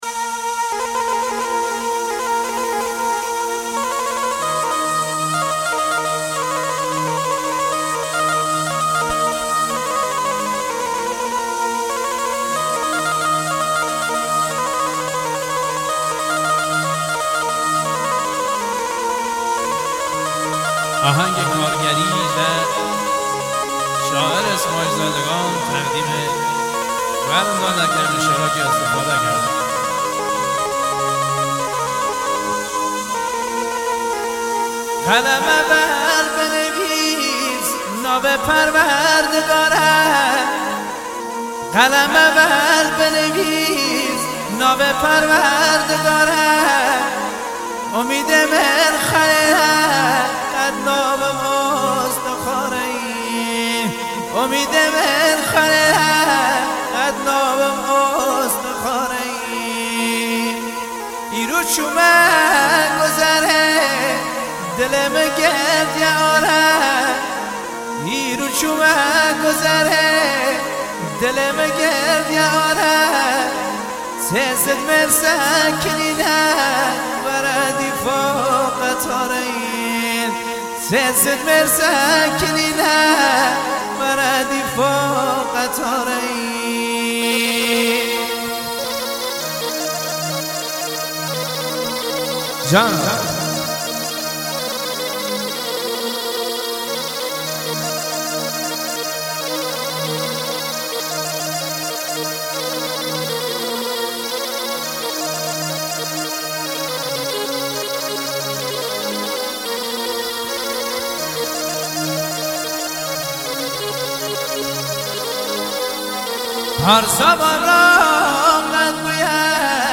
دانلود اهنگ کرمانجی